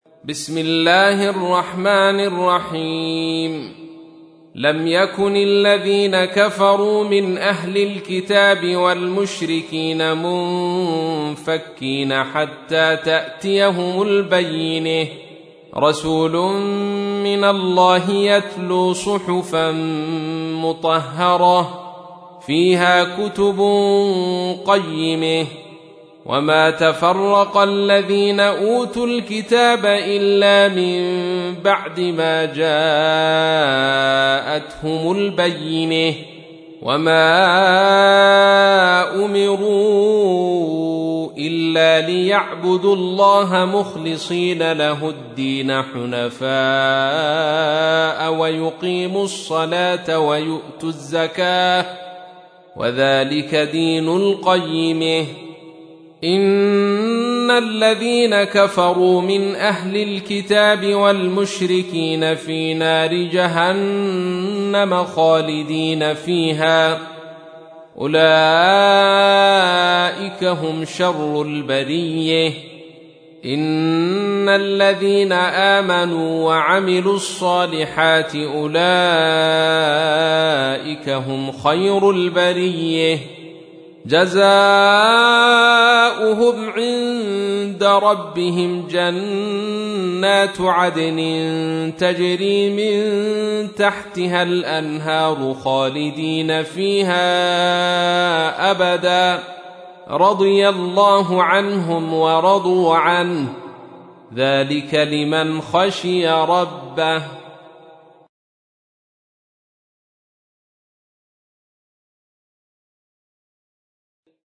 تحميل : 98. سورة البينة / القارئ عبد الرشيد صوفي / القرآن الكريم / موقع يا حسين